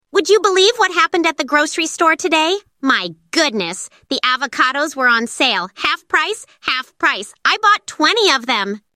ElevenLabs_Quirky Female English.mp3